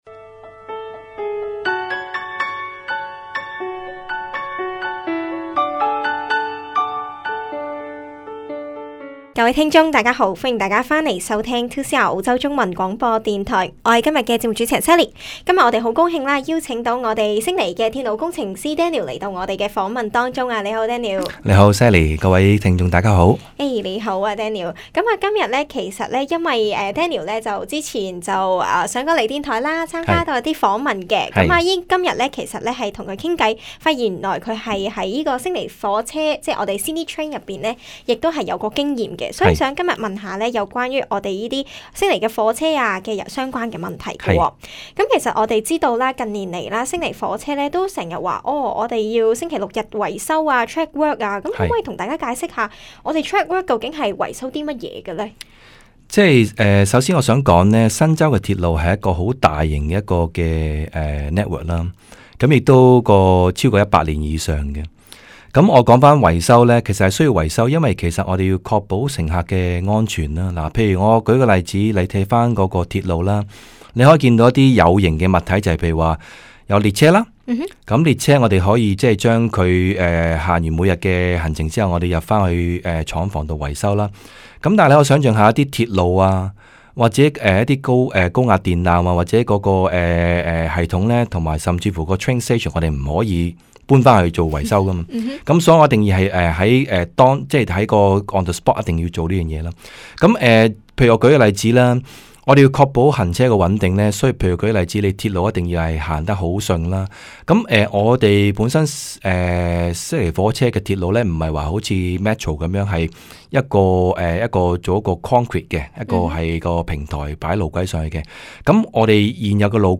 访问录音： https